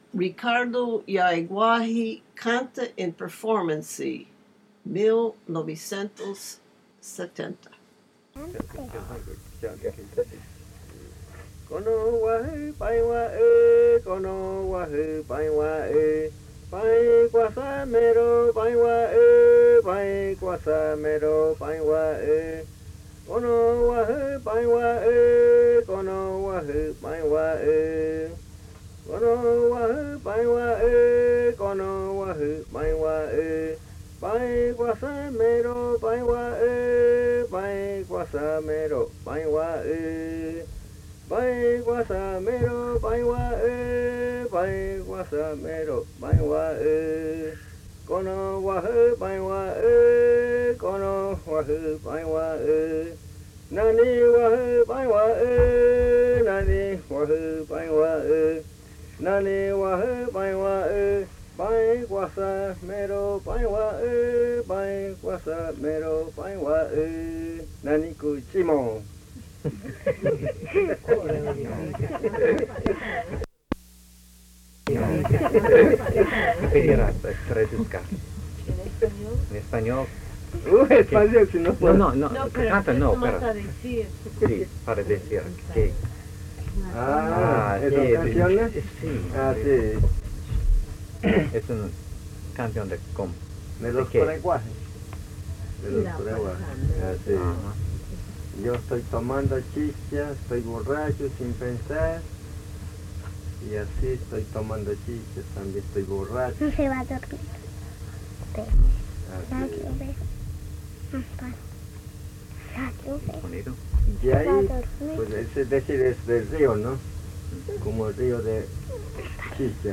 Cantos de chicha de los Secoya
Chicha songs
Buenavista, río Putumayo (Colombia)
canta en una reunión en su casa. Hay dos cantos sobre tomando chicha, y él indica que una es de los Secoya.